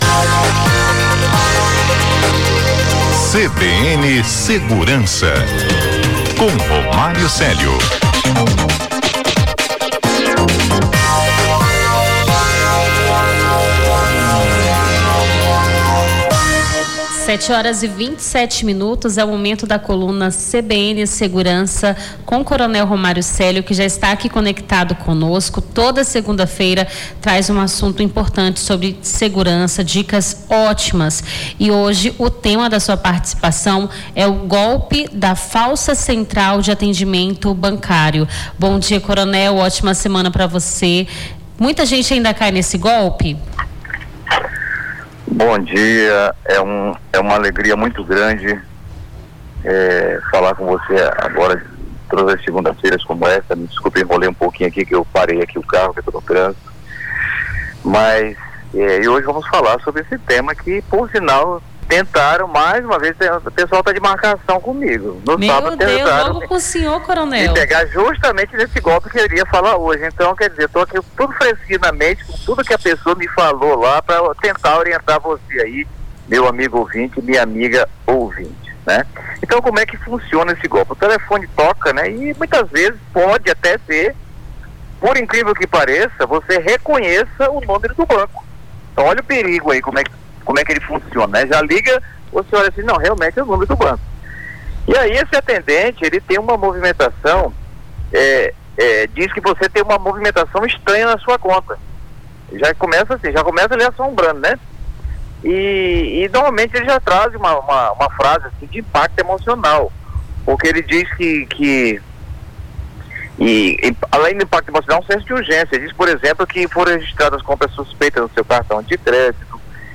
Conteúdo de responsabilidade do comentarista.